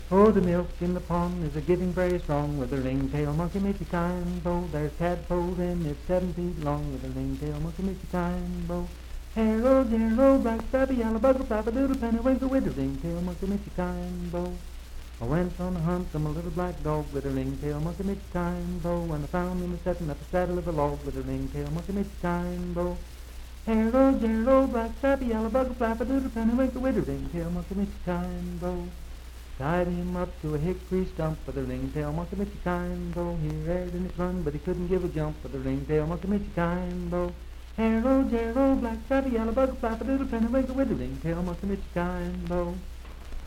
Unaccompanied vocal music
Verse-refrain 3d(6w/R).
Voice (sung)
Marlinton (W. Va.), Pocahontas County (W. Va.)